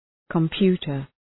{kəm’pju:tər}